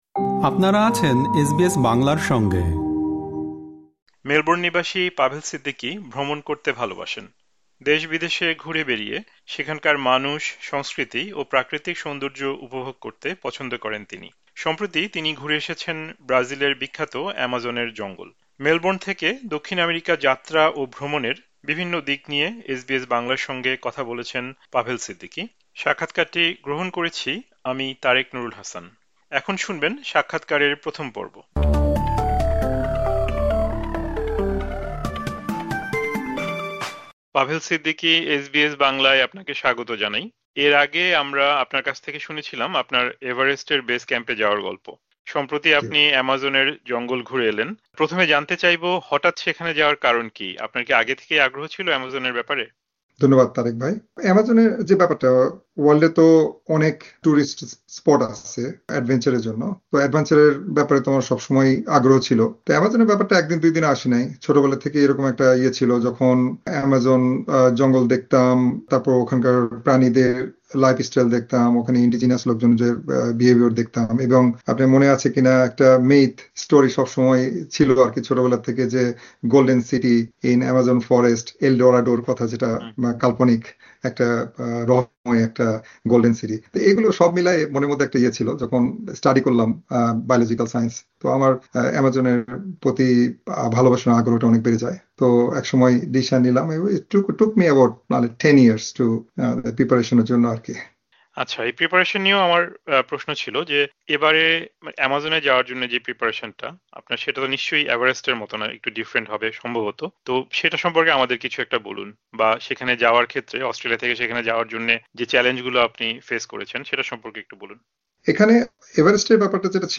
এখানে থাকছে সাক্ষাৎকারের প্রথম পর্ব।
সাক্ষাৎকারটি শুনতে উপরের অডিও-প্লেয়ারে ক্লিক করুন।